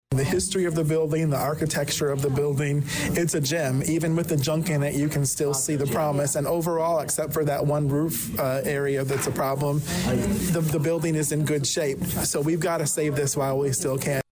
Mayor Rickey Williams, Jr also reflected on the importance of saving this building.  And he pointed out that demolition would be especially expensive, due to the complications of adjoining walls with other buildings.